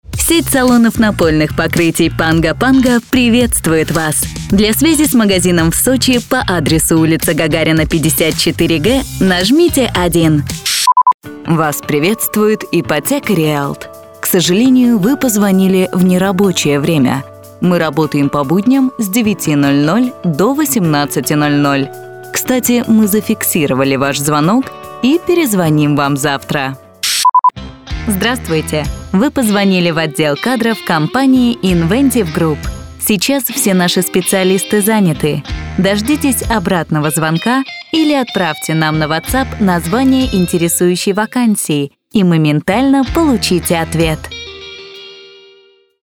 Commercial, Young, Natural, Friendly, Corporate
Telephony
- native russian speaker without region accent